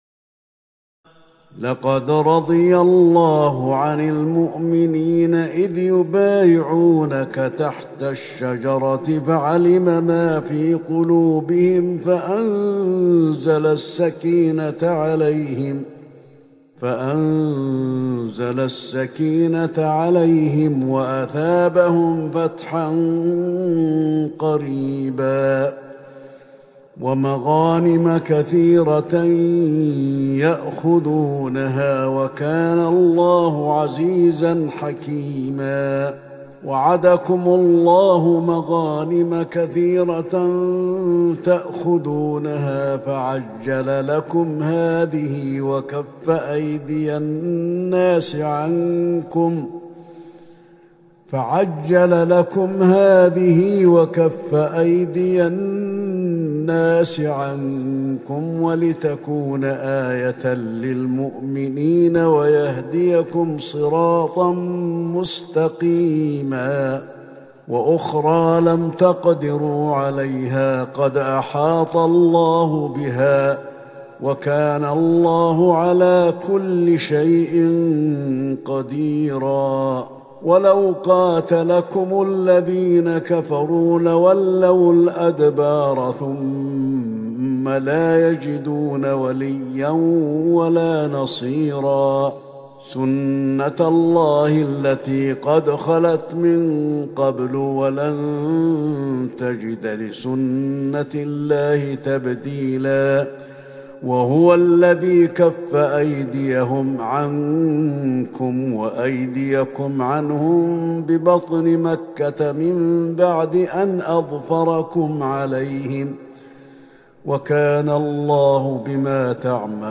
تهجد ٢٧ رمضان ١٤٤١هـ من سورة الفتح ١٨-النهاية و الحجرات ١-٨ > تراويح الحرم النبوي عام 1441 🕌 > التراويح - تلاوات الحرمين